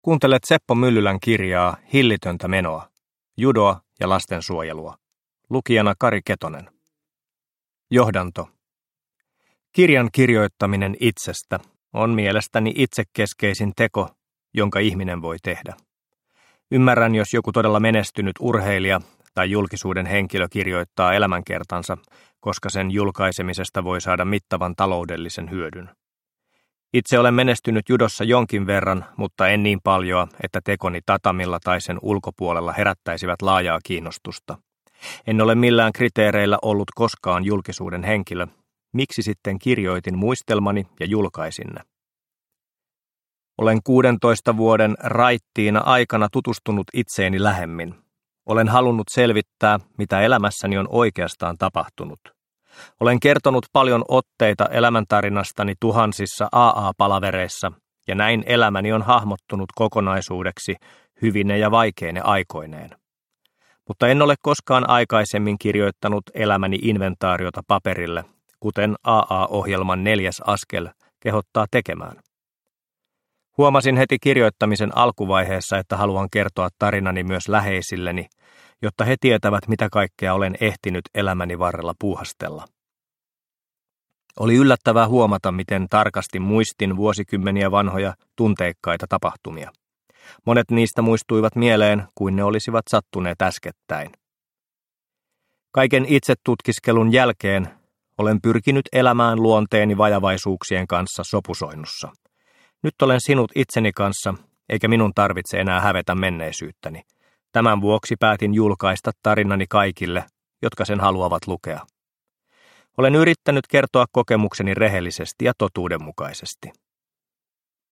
Hillitöntä menoa – Ljudbok – Laddas ner